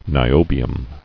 [ni·o·bi·um]